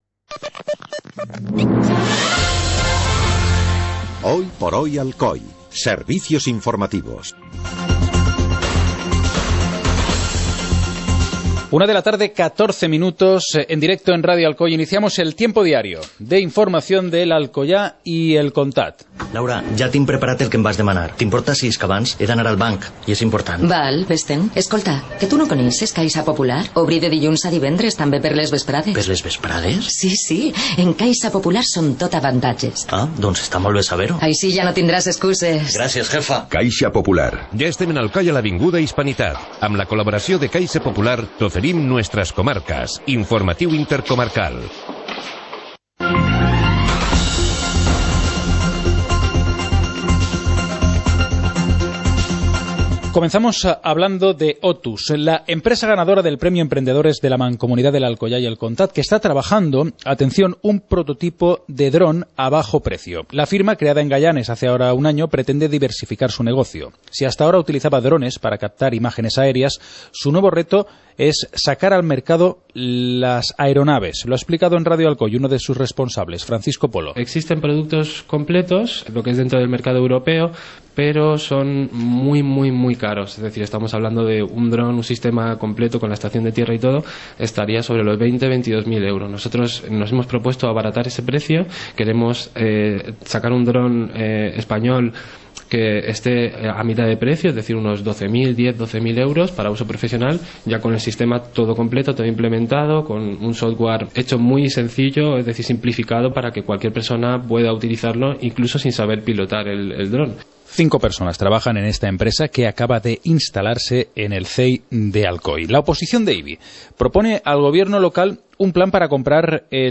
Informativo comarcal - jueves, 29 de enero de 2015